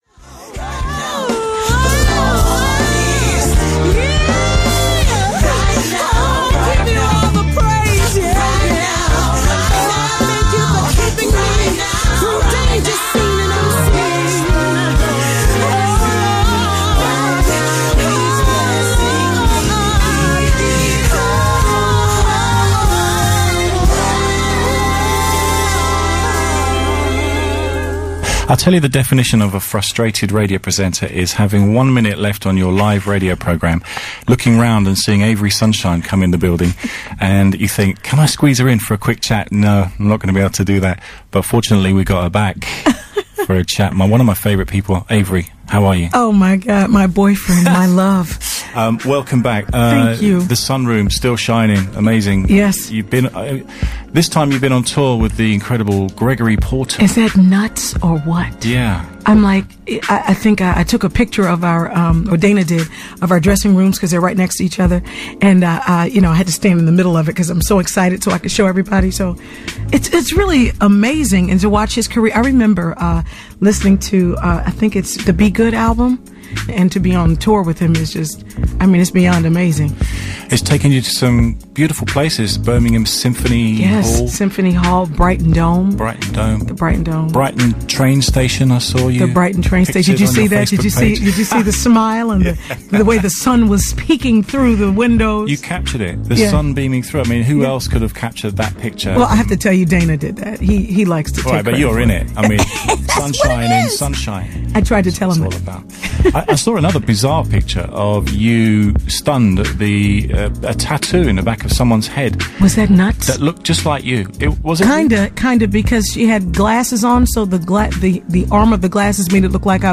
She also performed live on the piano.